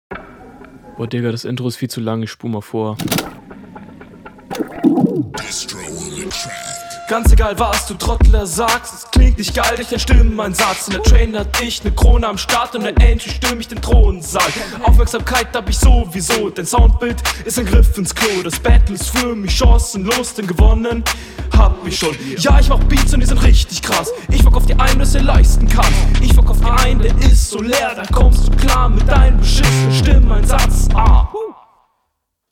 Einstieg komplett neben dem Takt.
Soundtechnisch ist mir für diesen knackigen Beat etwas zu viel Hall/Delay auf der Stimme, was …